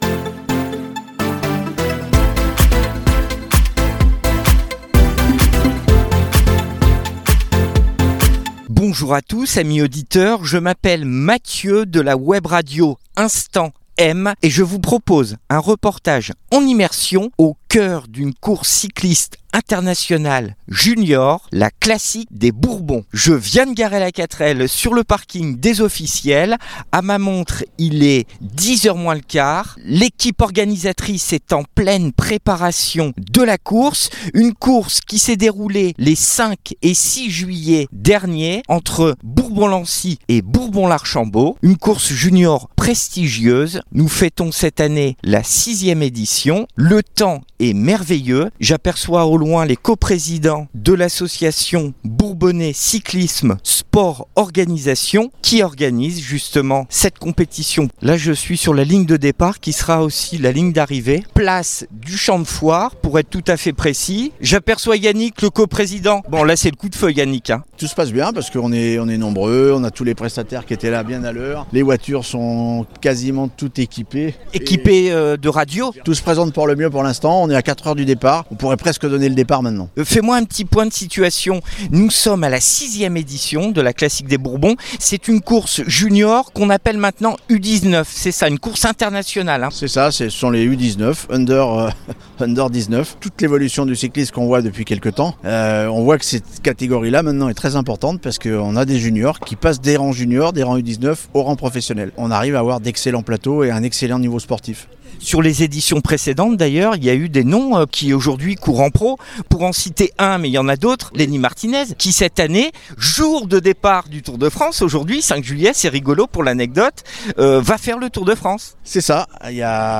Reportage
Reportage "en immersion" au coeur de la 6ème édition de La Classique des Bourbons (Course cycliste U19), qui s'est déroulée les 5 et 6 juillet derniers entre Bourbon-Lancy et Bourbon-l'Archambault